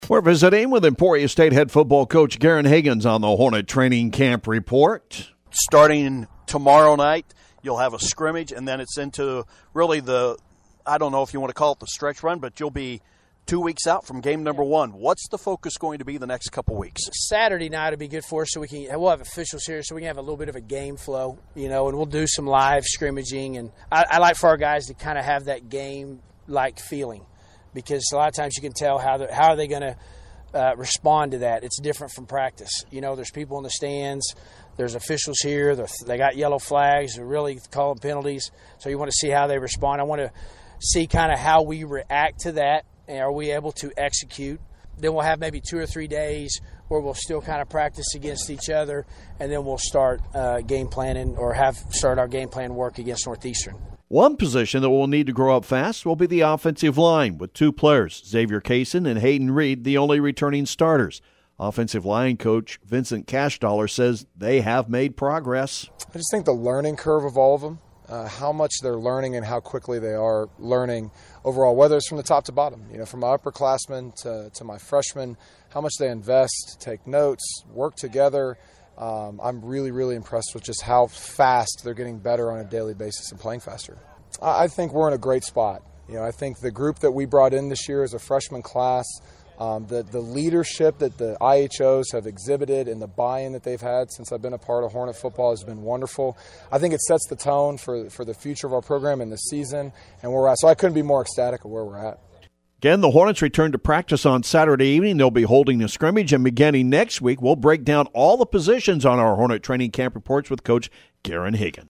Emporia State football training camp report